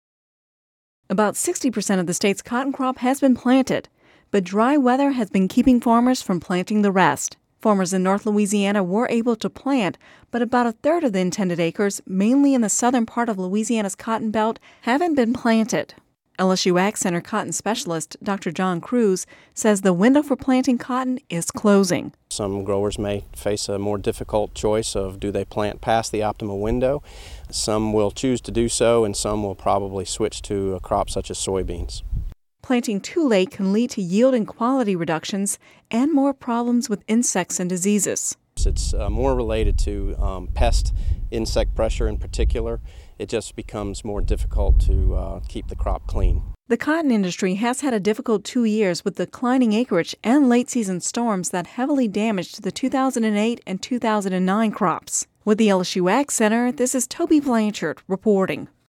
Radio News 05/24/10